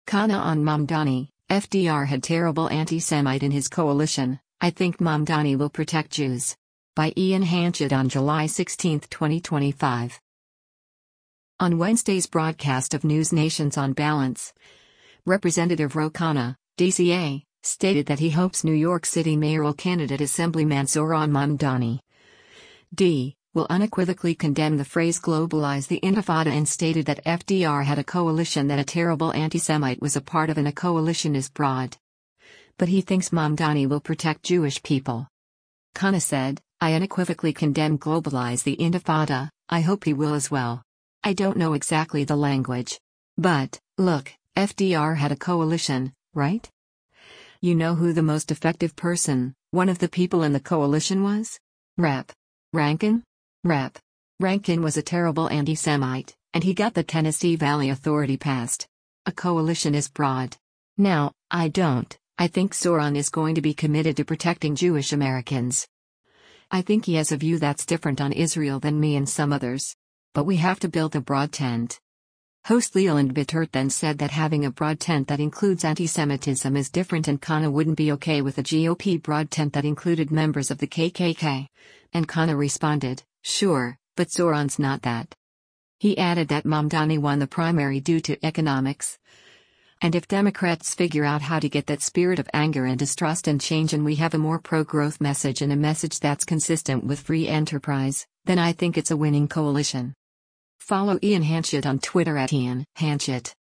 On Wednesday’s broadcast of NewsNation’s “On Balance,” Rep. Ro Khanna (D-CA) stated that he hopes New York City mayoral candidate Assemblyman Zohran Mamdani (D) will unequivocally condemn the phrase “globalize the intifada” and stated that FDR had a coalition that “a terrible antisemite” was a part of and “A coalition is broad.”